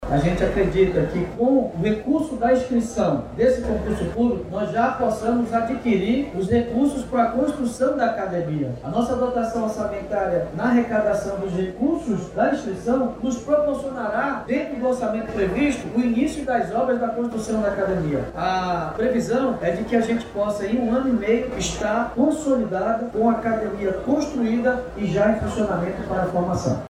A construção da Academia de Polícia vai contar com recursos oriundos do próximo concurso público da corporação e deve estar em funcionamento dentro do período de um ano e seis meses, afirma o prefeito David Almeida.